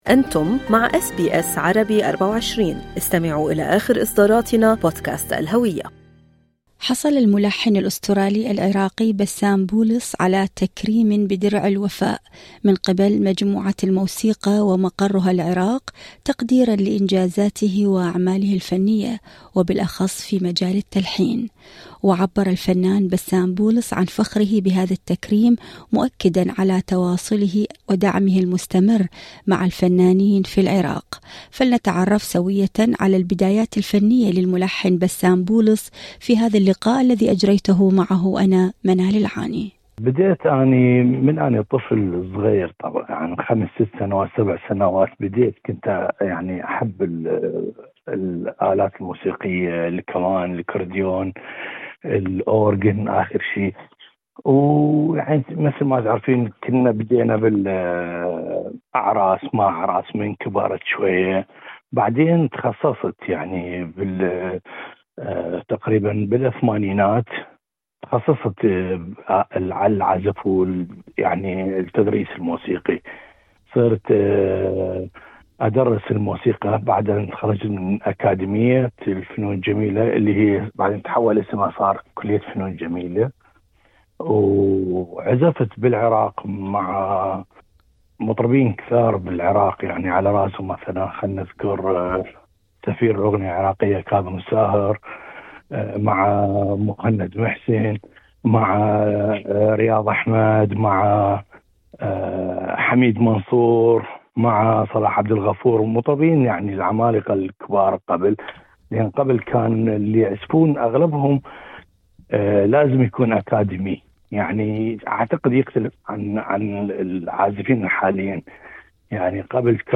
المزيد في المقابلة الصوتية اعلاه هل أعجبكم المقال؟